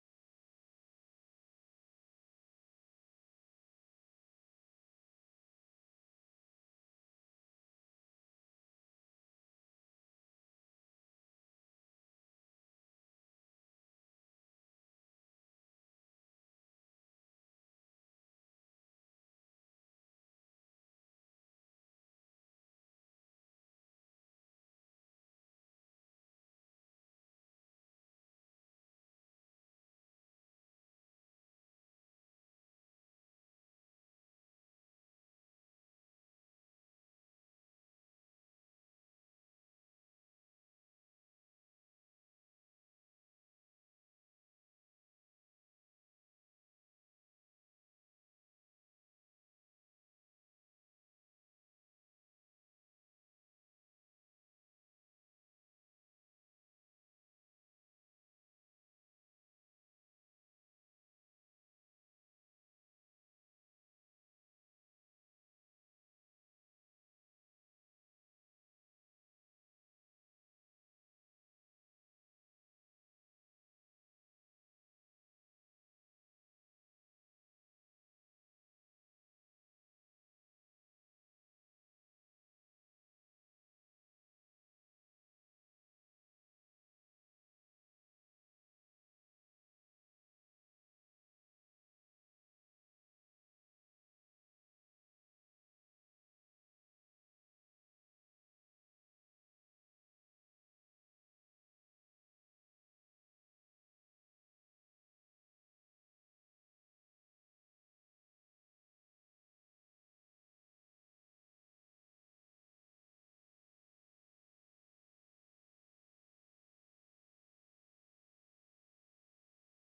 Nghe Mp3 thuyết pháp Hoàn Cảnh Nào Cũng Tu
giảng tại thiền đường Mây Từ